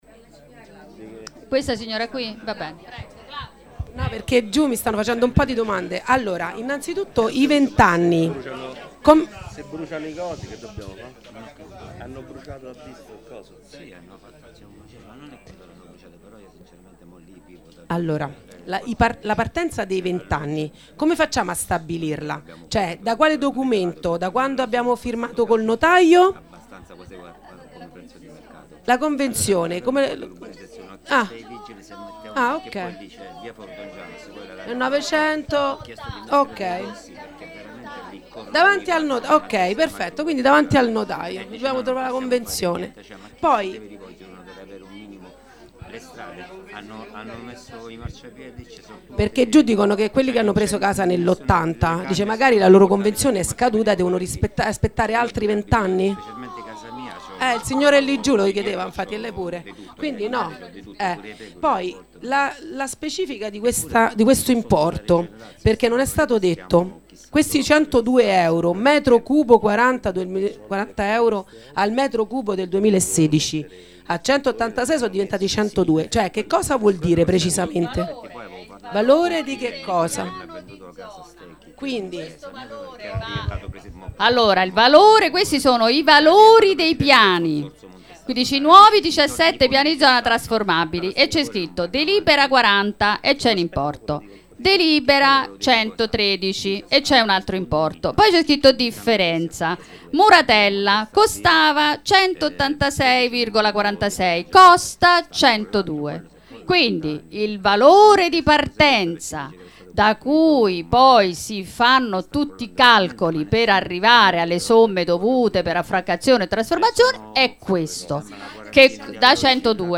Il 6 ottobre 2023 si è svolto l'incontro pubblico sulla Trasformazione del diritto di superficie in diritto di piena proprietà relativo al Piano di Zona B38 Muratella.
Interventi e domande dei cittadini
cittadina